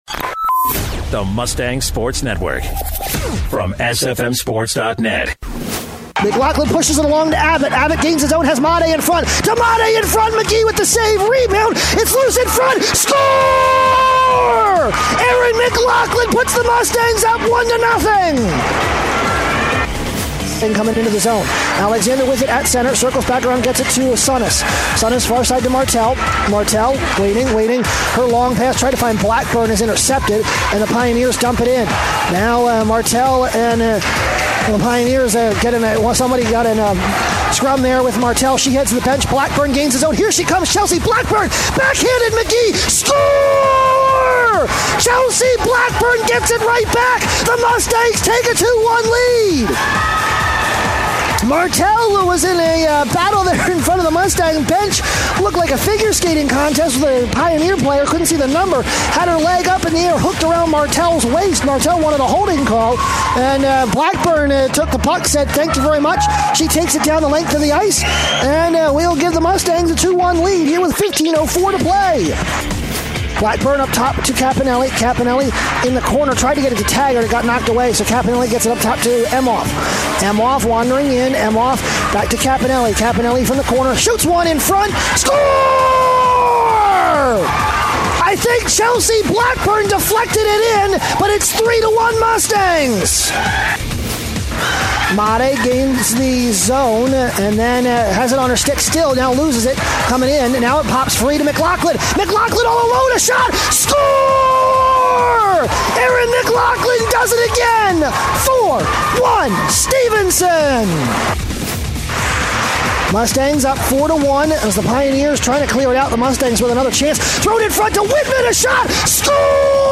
Audio highlights from Stevenson's sweep at Sacred Heart, closing out the weekend series with a 6-1 win.